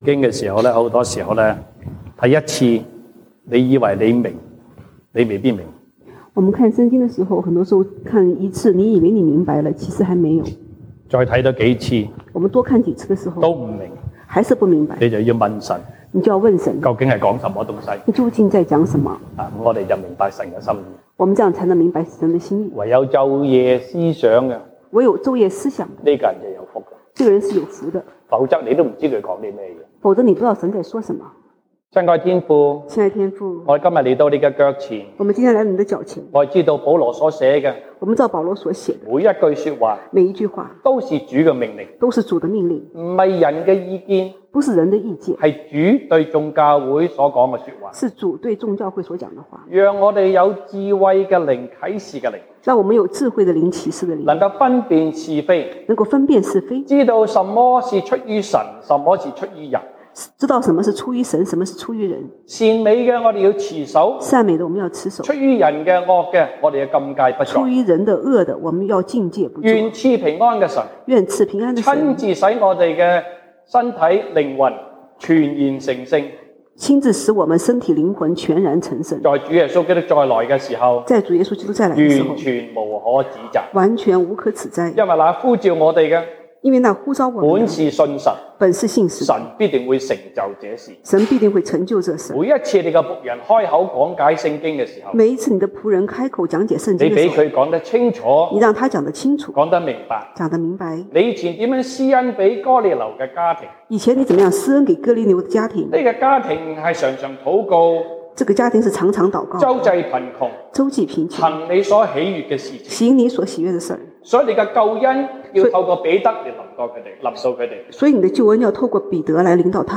Sunday Service Chinese